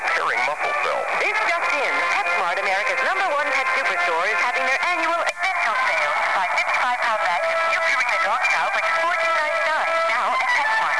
Each test starts in with the loose setting, then changes to the sharp setting about half way through.
1.80 kHz USB Mode
My observations: For the 1.80 kHz test, the audio sounds much worse with the sharp setting.
There is no apparent volume change in the 1.80 kHz case.
1.80LooseSharp.WAV